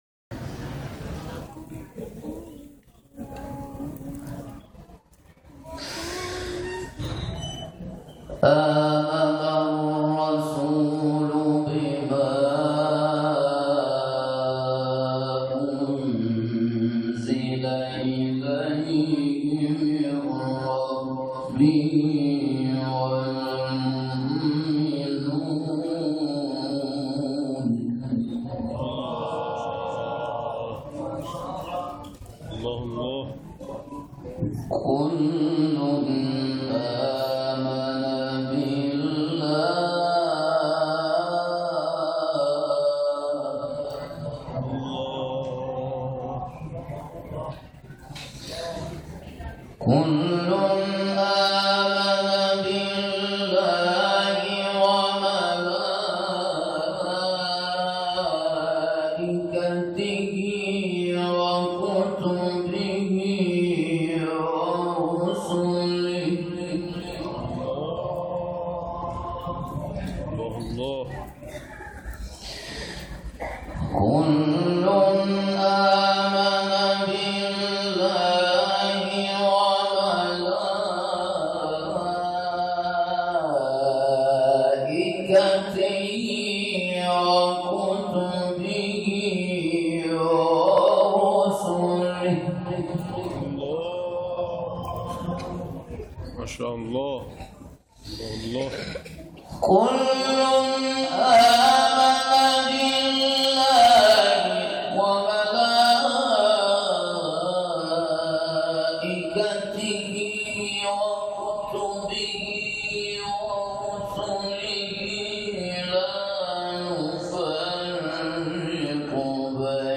گروه جلسات و محافل ــ محفل انس با قرآن کریم شامگاه شنبه، هشتم دی‌ماه در شهرستان شهریار برگزار شد.